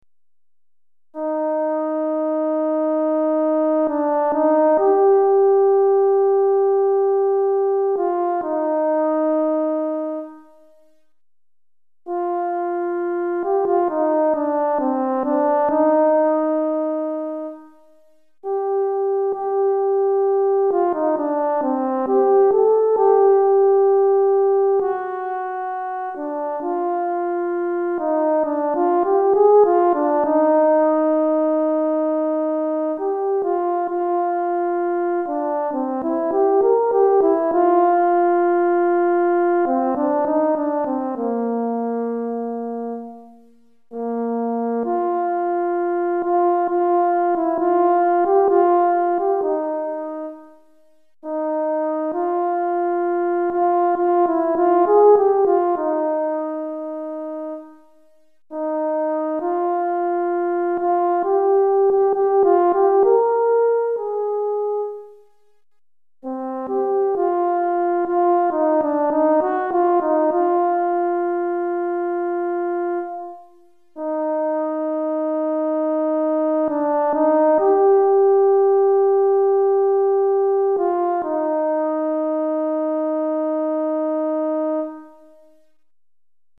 Cor en Fa Solo